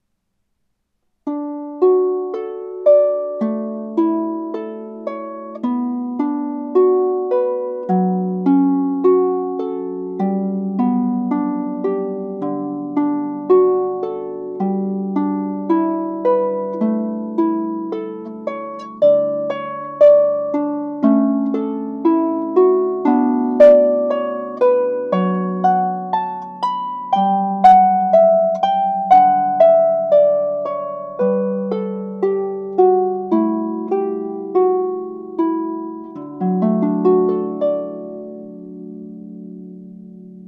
Harpist
Classical and Wedding